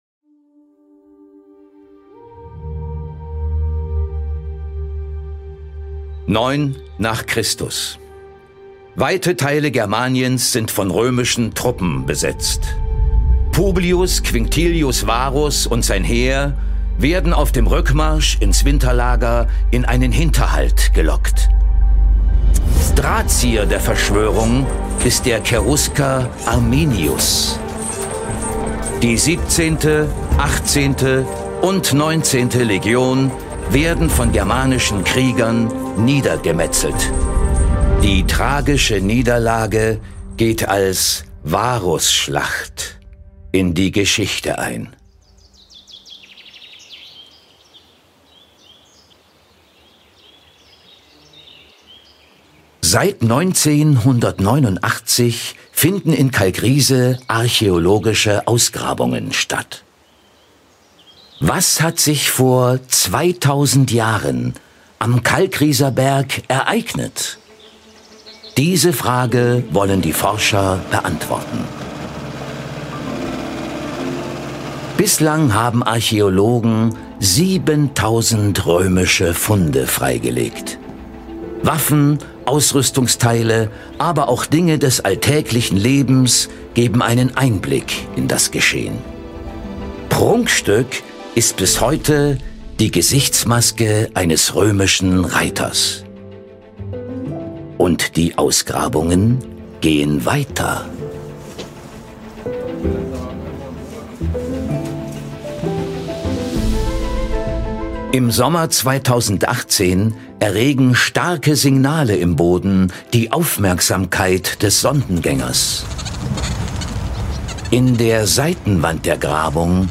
Fernseh-Doku: Projekt Schienenpanzer
als Erzähler in der Fernseh-Doku über den weltweit ersten komplett aufgefundenen römischen Schienenpanzer Ein Jahrhundertfund in Kalkriese